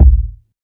KICK.36.NEPT.wav